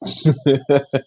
Laugh